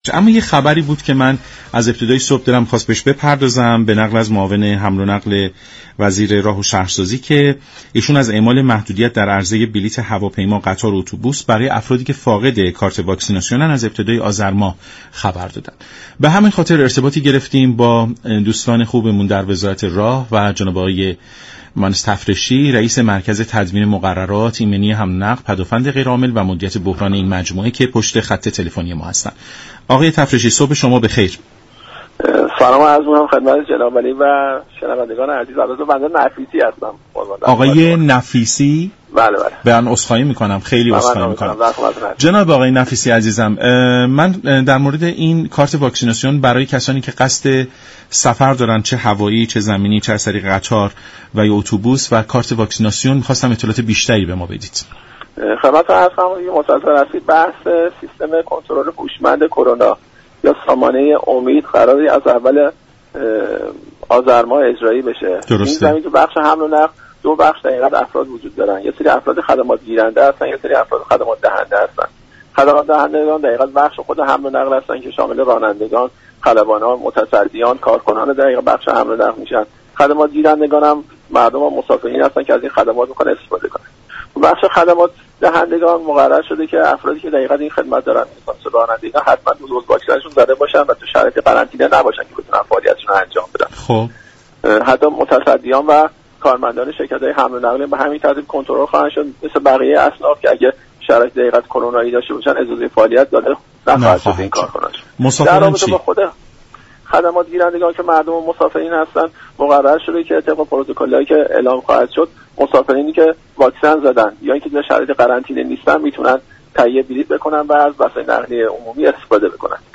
به گزارش شبكه رادیویی ایران، رضا نفیسی رئیس مركز تدوین مقررات ایمنی حمل و نقل پدافند غیرعامل و مدیریت بحران وزارت راه و شهرسازی در برنامه «سلام صبح بخیر» رادیو ایران از جزئیات ممنوعیت هوشمند سفر خبر داد و گفت: سامانه امید (سیستم كنترل هوشمند كرونا) از آذرماه اجرایی خواهد شد، كسانی كه در بخش حمل و نقل فعالیت می كنند حتما باید دو دوز واكسن خود را تزریق كرده باشند و هیچ یك از آنها در شرایط قرنطینه قرار نداشته باشند.